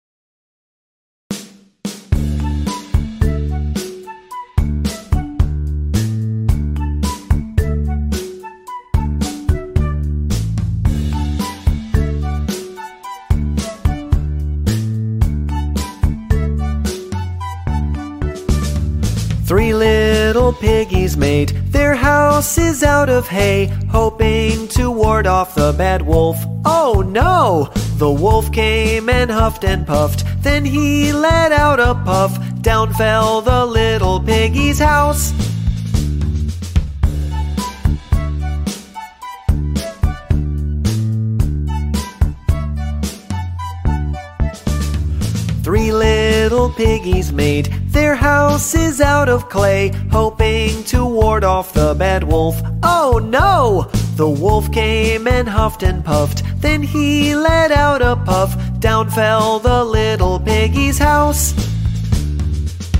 Nursery Rhymes & Kid Songs ｜ Children's Music